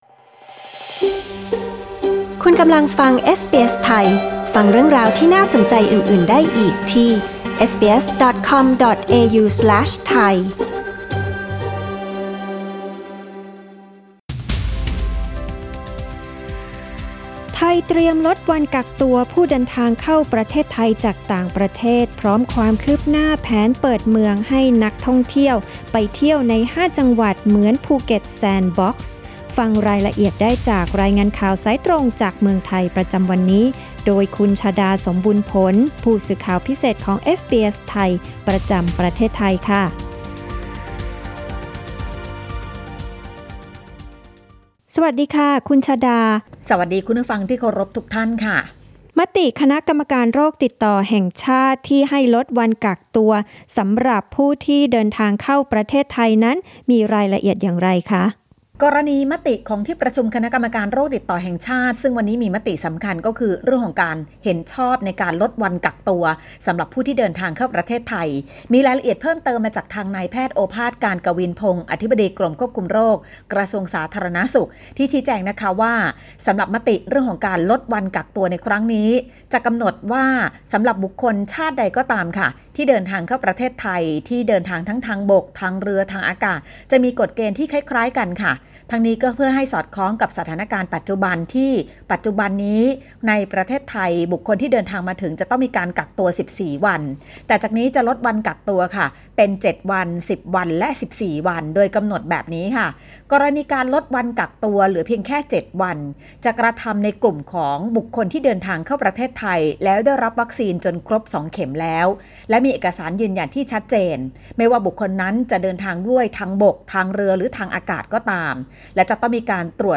รายงานสายตรงจากเมืองไทย โดยเอสบีเอส ไทย Source: Pixabay